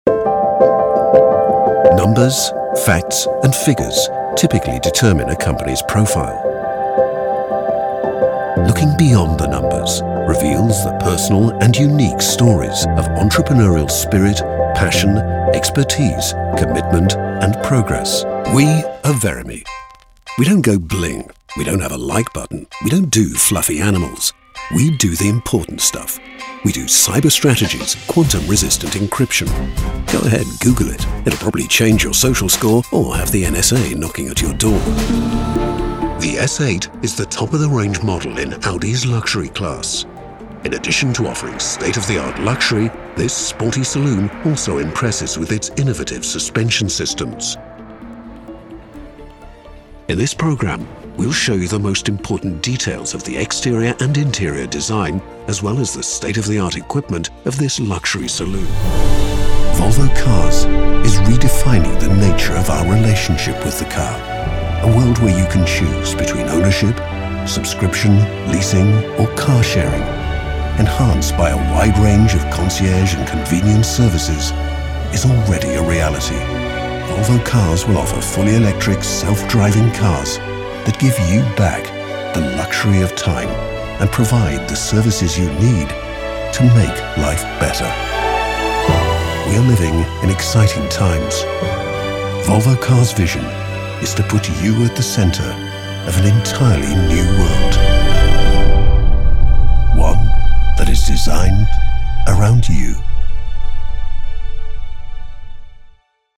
Vídeos Corporativos
Minha voz é geralmente descrita como calorosa, natural e distinta e é frequentemente usada para adicionar classe e sofisticação a projetos de publicidade e narrativa.